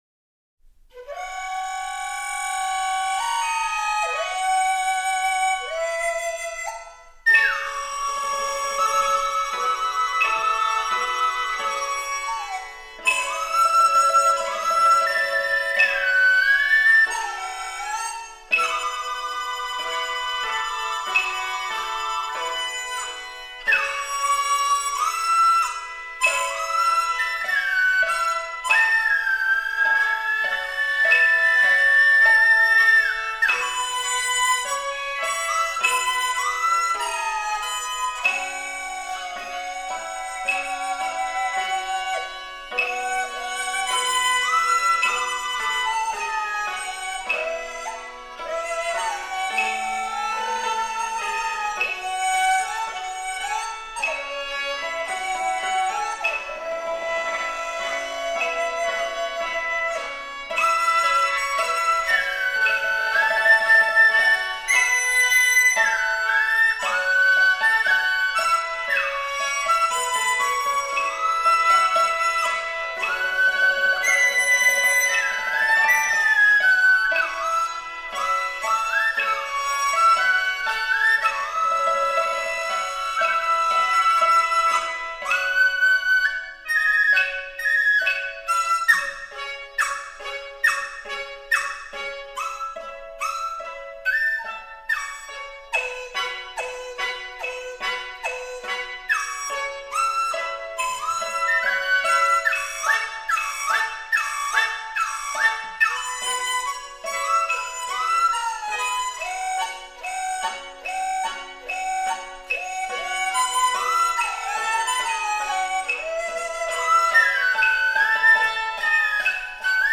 梆笛
曲中变换运用剁音、历音、吐音、滑音、花舌音、飞指颤音等，使乐曲展现出梆笛演奏艺术的独特风韵和浓烈的地方色彩。
[一]慢板，曲调优美委婉如歌，具有洒脱的语言化特点和北方人民豪爽乐观的性格特征。[二]明快有力又恢谐风趣。